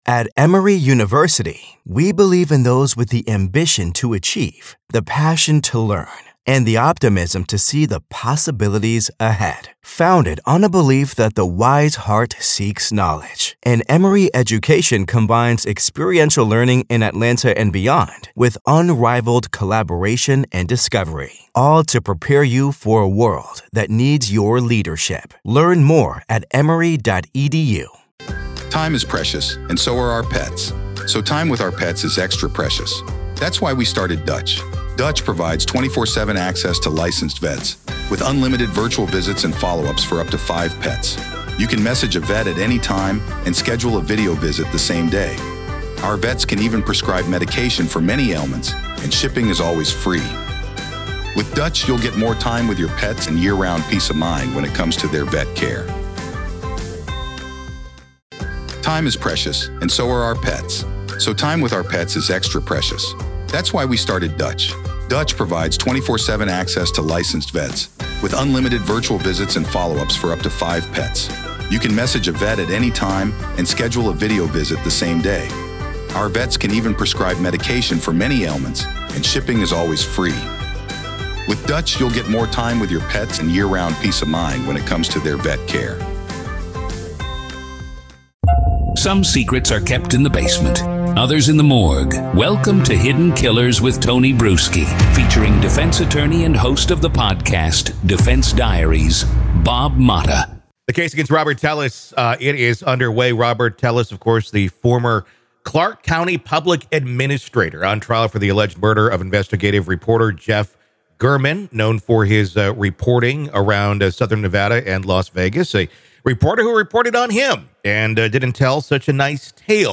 The conversation highlights the challenges facing the defense and anticipates the trial's continuation, especially with Telles expected to testify.